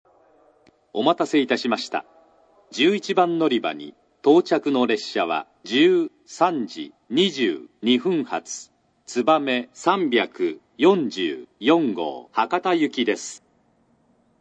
スピーカー：安全柵埋込型（新幹線）
接近放送 つばめ344号・博多　 (71KB/14秒)
奇数番線（上り）は男性、偶数番線（下り）が女性の構成です。